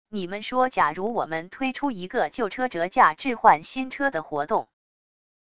你们说假如我们推出一个旧车折价置换新车的活动wav音频生成系统WAV Audio Player